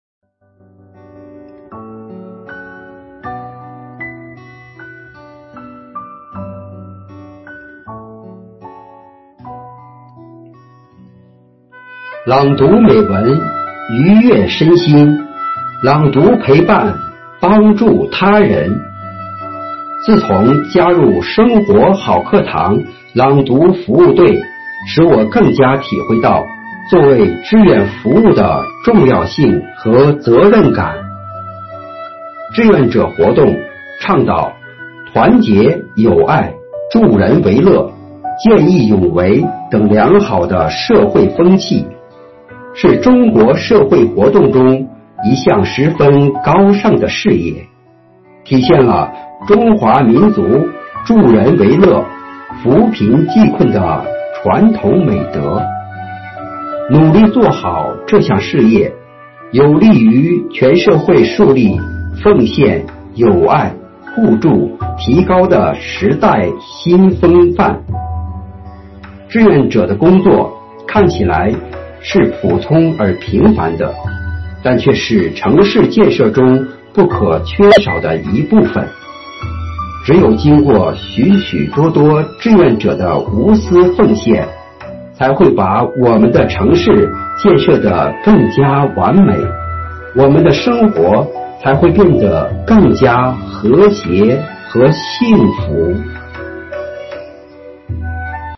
暨中华诗韵支队第13场幸福志愿者朗诵会